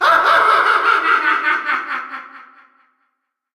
File:Crazy Hand Laugh (Brawl).oga
Voice clip from Super Smash Bros. Brawl
Crazy_Hand_Laugh_(Brawl).oga.mp3